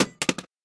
main Divergent / mods / Soundscape Overhaul / gamedata / sounds / material / small-weapon / collide / hithard03hl.ogg 9.1 KiB (Stored with Git LFS) Raw Permalink History
hithard03hl.ogg